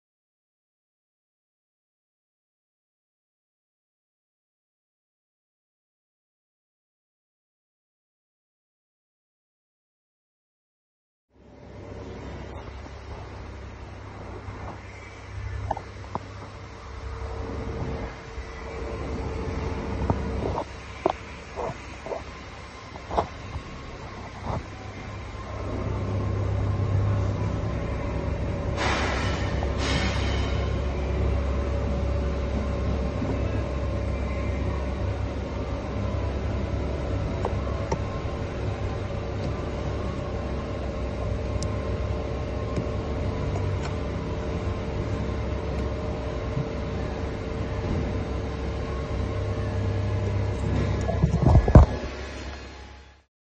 BMW 216d B37 Engine sound effects free download